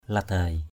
/la-d̪aɪ/ (cv.) lidai l{=d (d.) bễ (ống thổi bễ lò rèn) = soufflet de forge. duei ladai d&] l=d kéo bễ = souffler avec le soufflet de forge. glaoh ladai...